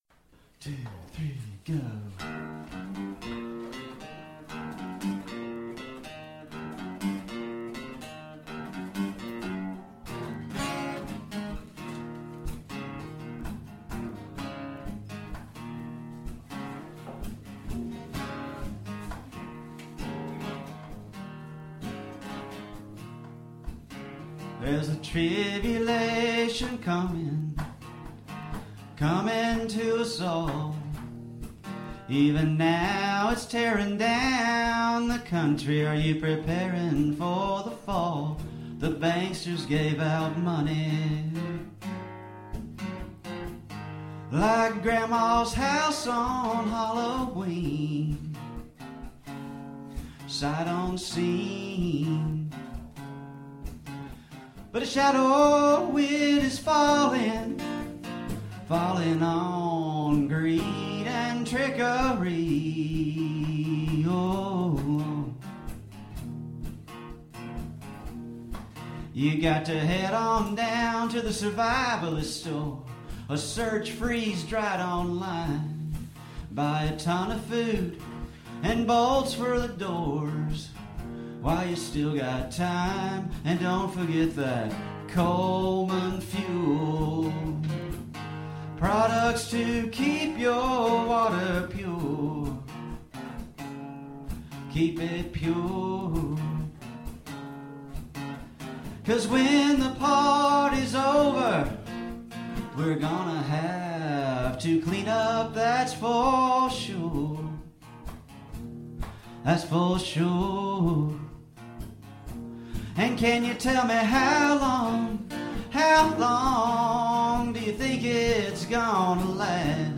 Tune guitar down ½ step! Play E